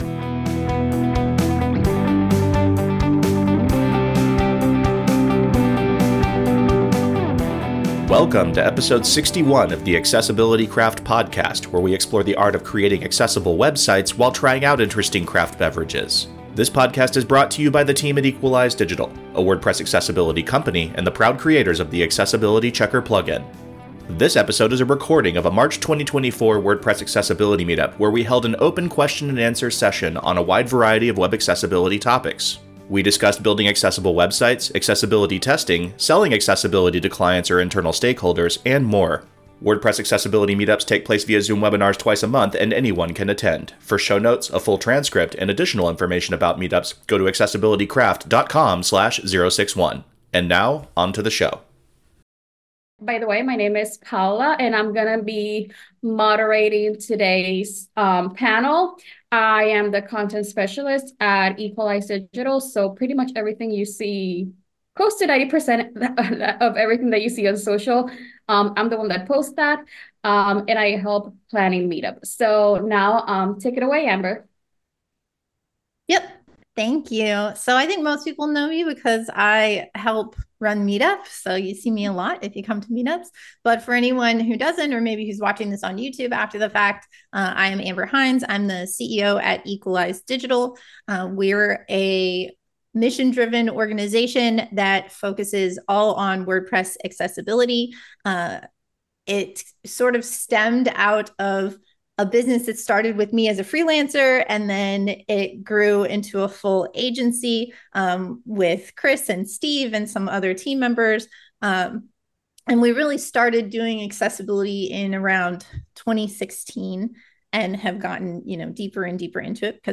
This episode is a recording of a March 2024 WordPress Accessibility Meetup, where we held an open question-and-answer session on a wide variety of web accessibility topics. We discussed building accessible websites, accessibility testing, selling accessibility to clients or internal stakeholders, and more. WordPress Accessibility Meetups take place via Zoom webinars twice a month, and anyone can attend.